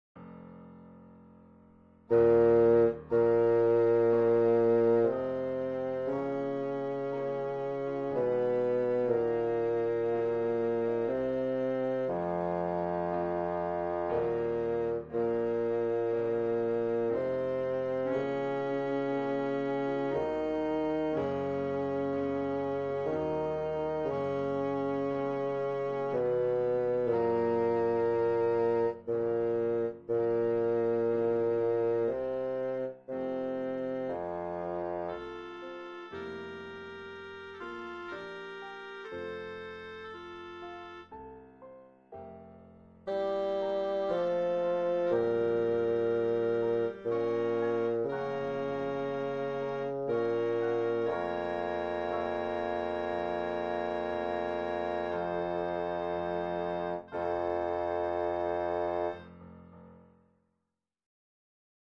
avec chaque voix accentuées
et parfois l'orchestre en fond